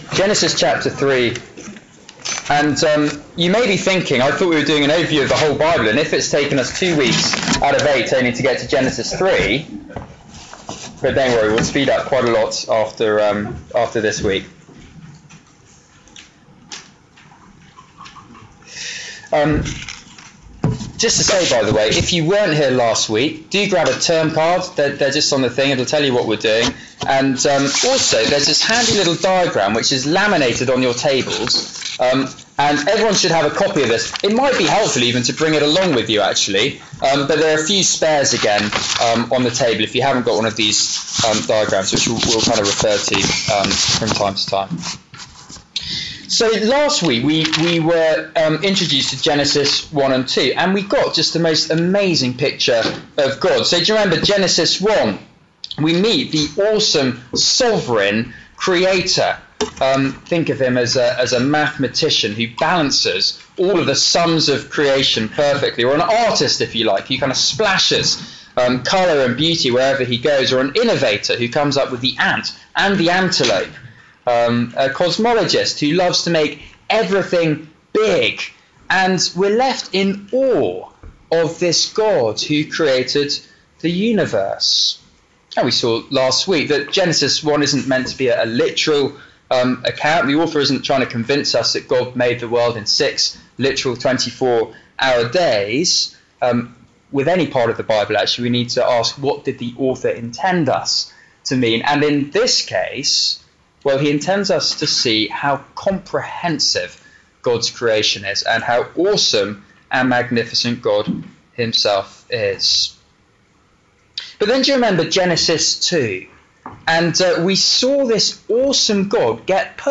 Media for Seminar